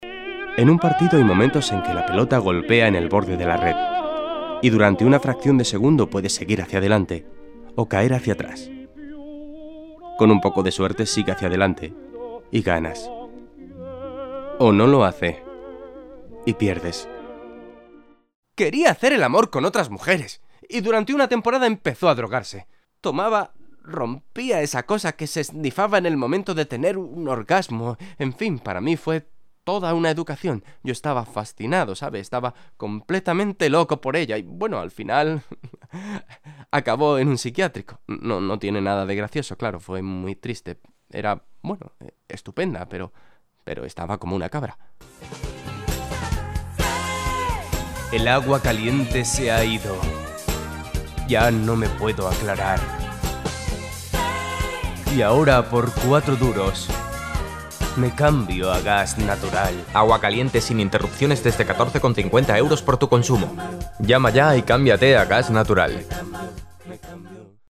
Voz joven, cálida y con brillo. Entonación especial, modulada, y con gran variedad de registros
kastilisch
Sprechprobe: Sonstiges (Muttersprache):